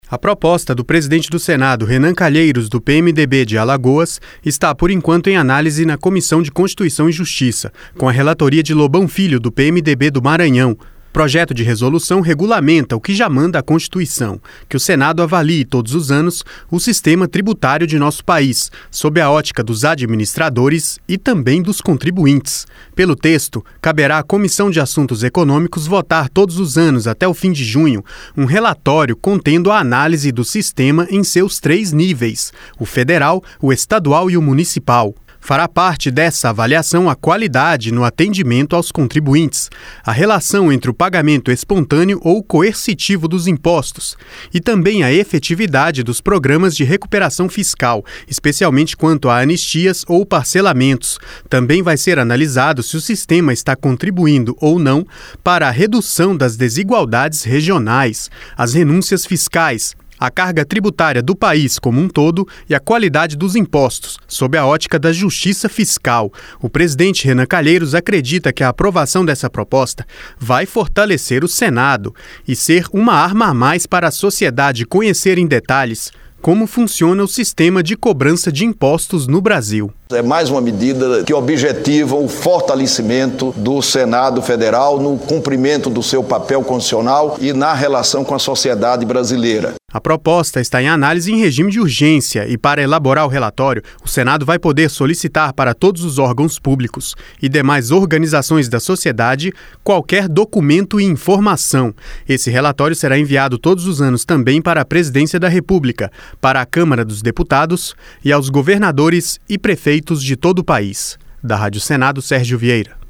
(RENAN CALHEIROS): É mais uma medida que objetiva o fortalecimento do Senado Federal no cumprimento do seu papel constitucional e na relação com a sociedade brasileira.